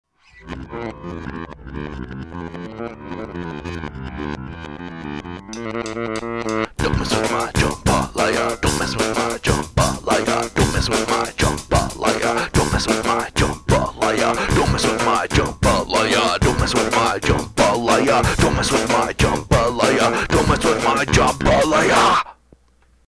Many of the vocal tracks are improvised lyrics and melodies.